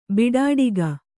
♪ biḍāḍiga